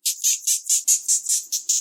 laugh_moth.ogg